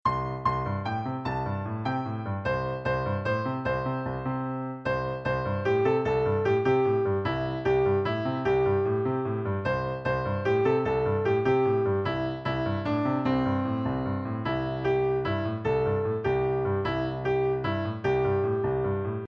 Sheet Music — Piano Solo Download
Piano Solo
Downloadable Instrumental Track